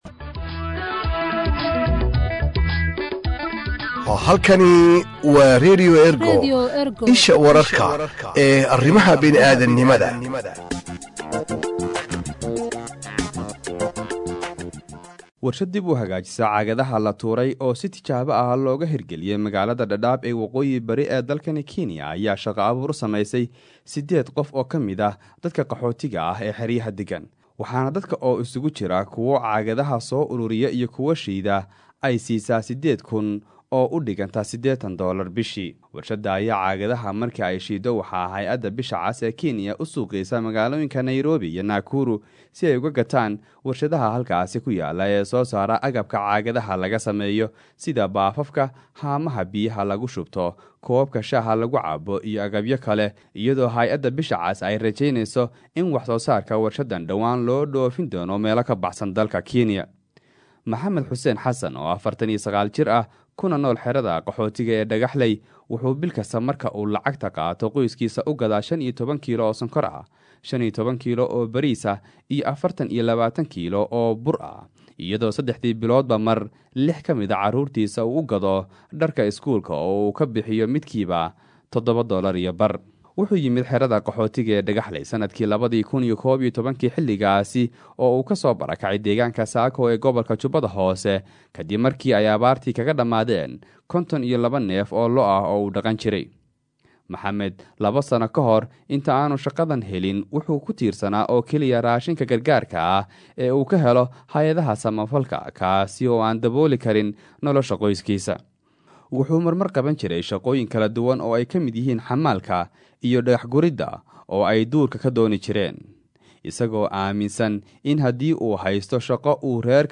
warbixin-dhaddaab.mp3